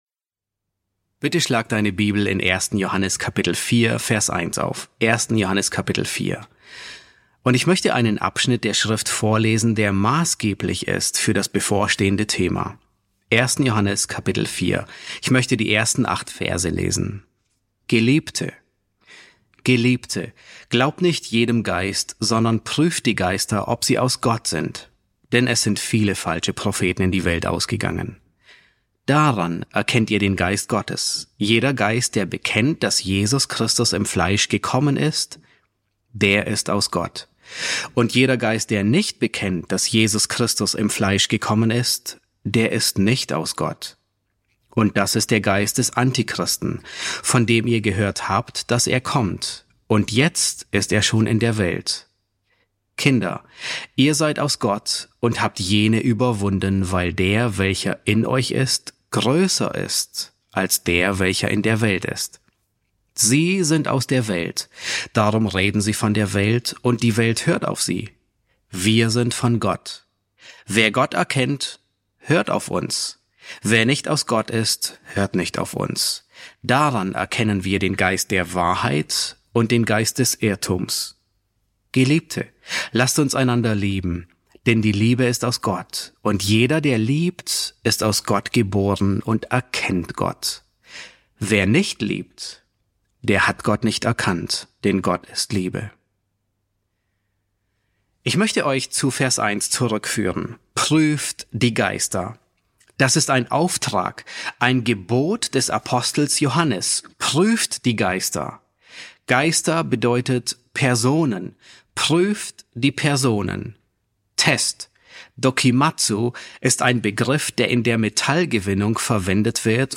S5 F2 | Die Geister prüfen ~ John MacArthur Predigten auf Deutsch Podcast